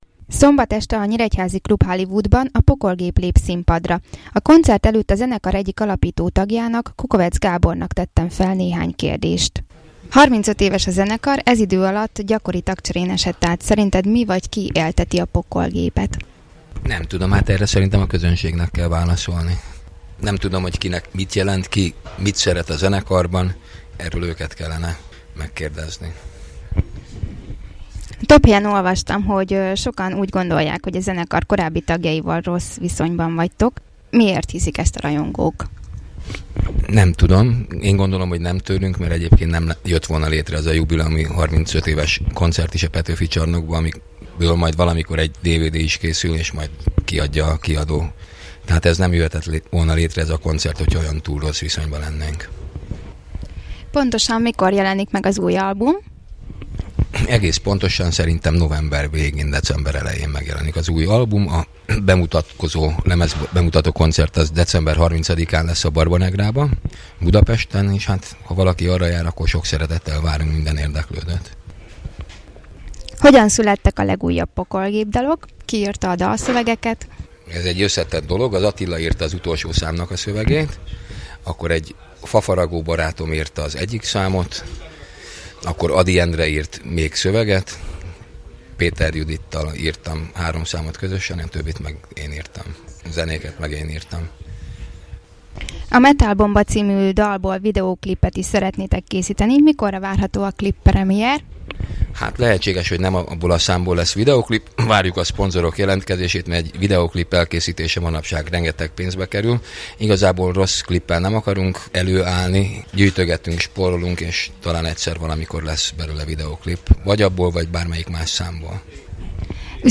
A fotók nézegetése közben hallgassátok meg a beszélgetést, amit a lejátszás gombra kattintva tehettek meg.